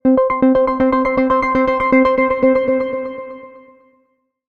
incoming-call.wav